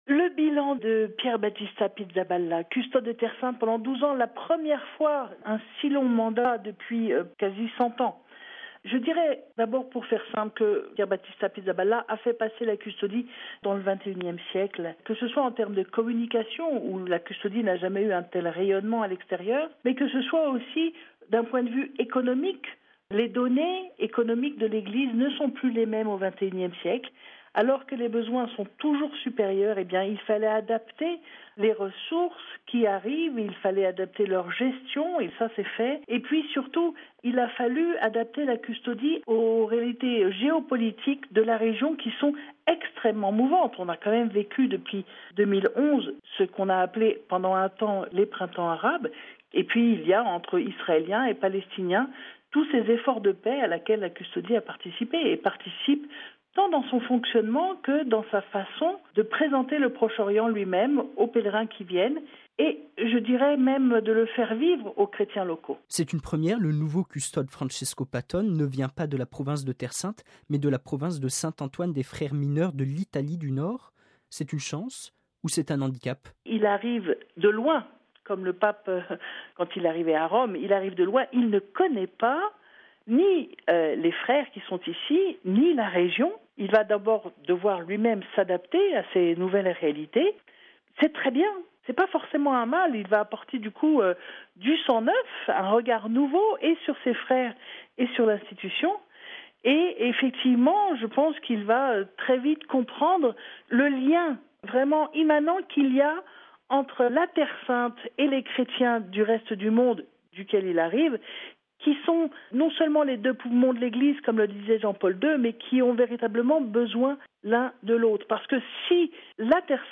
(RV) Entretien - Il devra coordonner l’accueil des pèlerins, garder les Lieux Saints et prendre soin de quelque 300 frères franciscains disséminés dans la province de Terre sainte : en Israël et en Palestine, mais aussi sous les bombes en Syrie, près des réfugiés au Liban, en Jordanie et en Grèce ou encore auprès des pauvres d’Égypte.